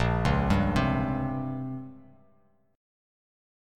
A#m6add9 Chord
Listen to A#m6add9 strummed